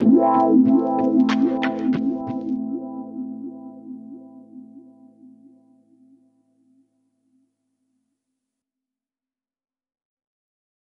HIT 9 .wav